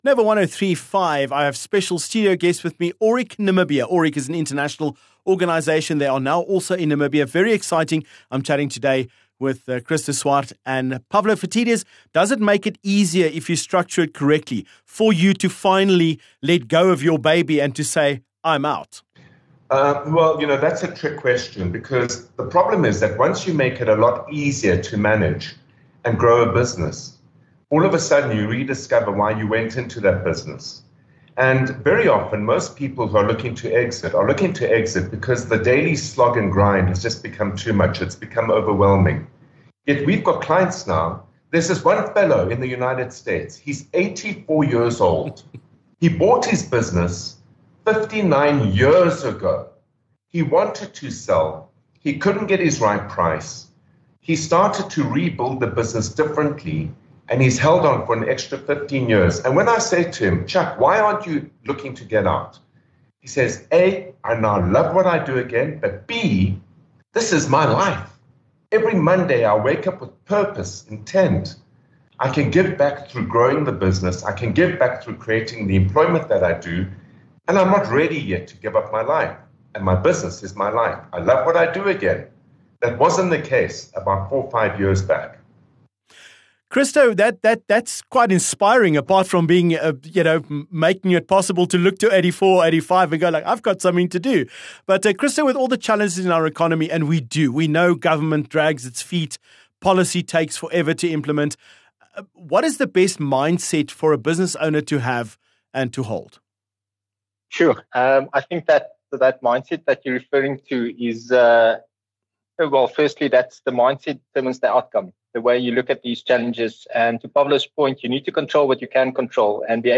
17 Oct Aurik Namibia Interview Part 2